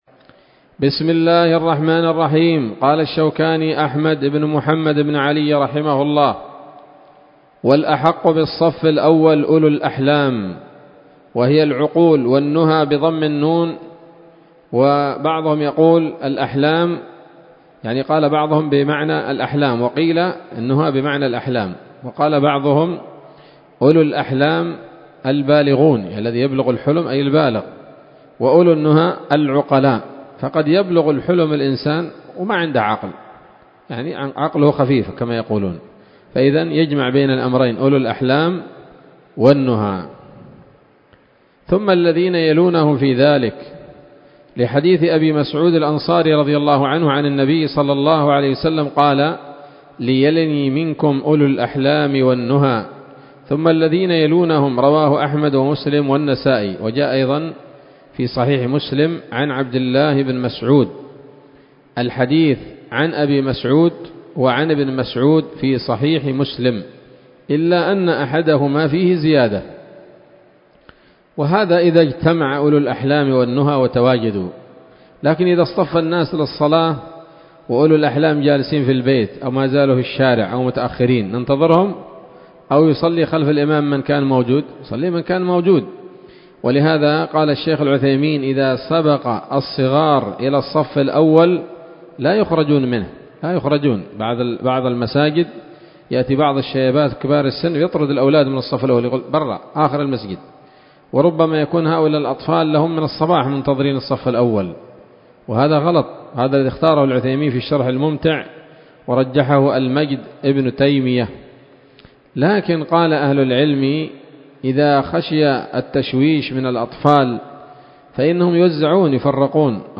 الدرس الثلاثون من كتاب الصلاة من السموط الذهبية الحاوية للدرر البهية